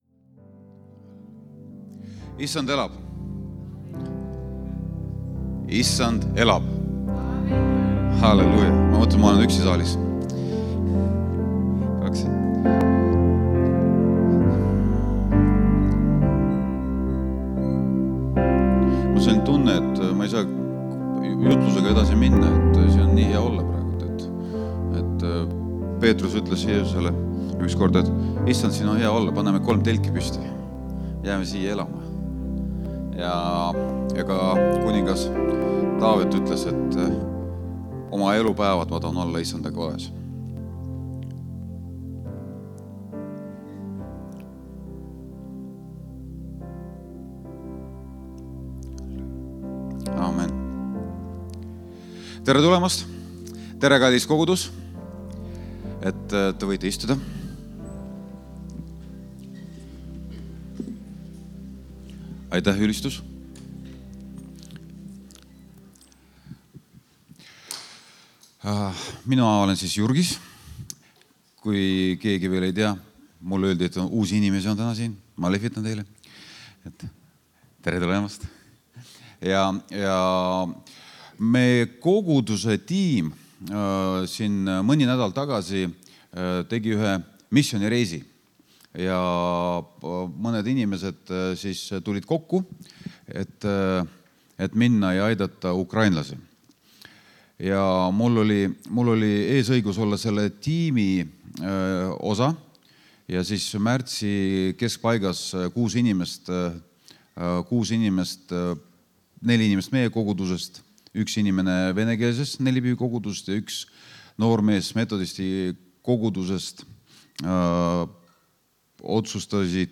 Jutlused - EKNK Toompea kogudus